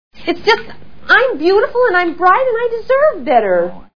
Manhattan Movie Sound Bites